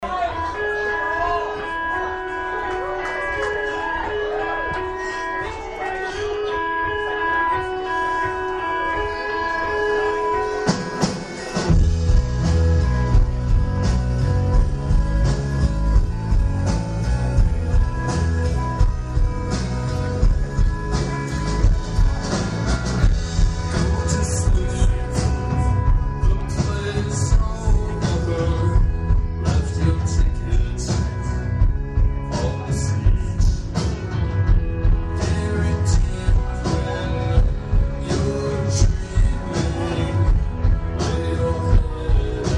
Dec. 9, 2011: Star Theater - Portland, OR